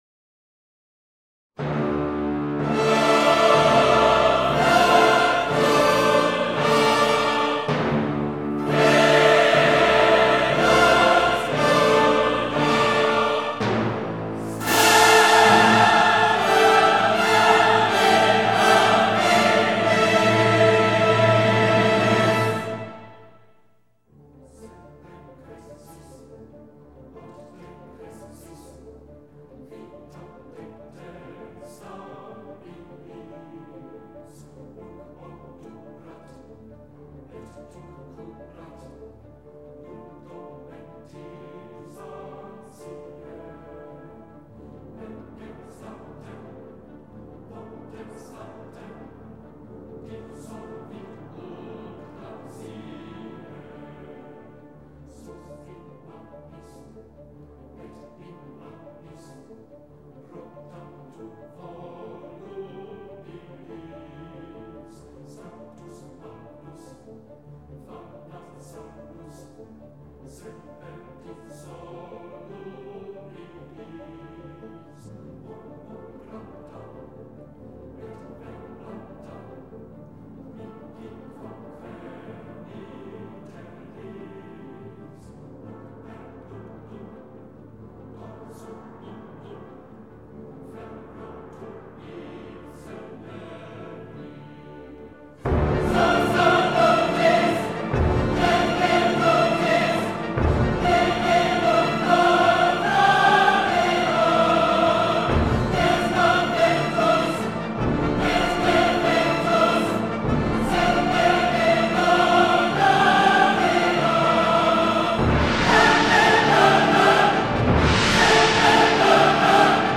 好有一种鲜血淋漓的感觉啊！